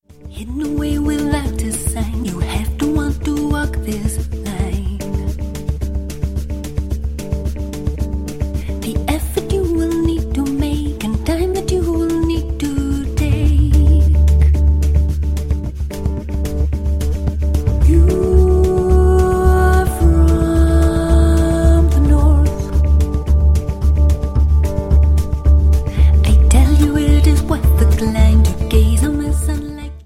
• Sachgebiet: Celtic